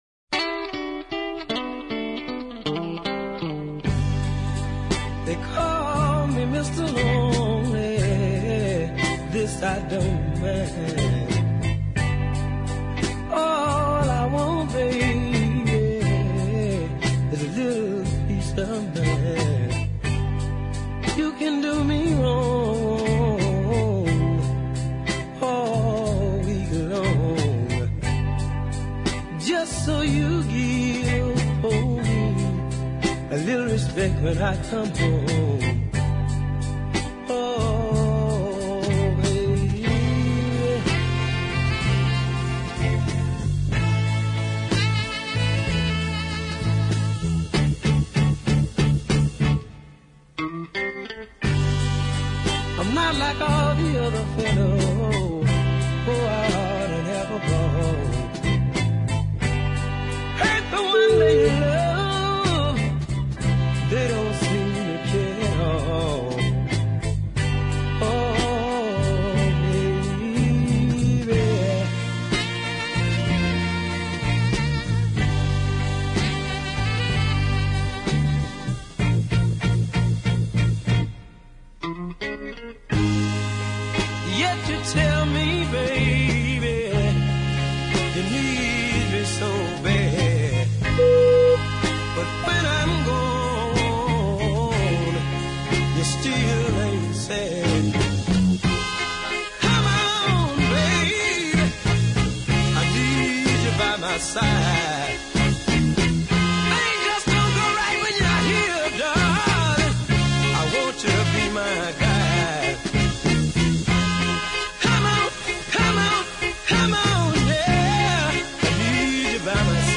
excellent deep soul pieces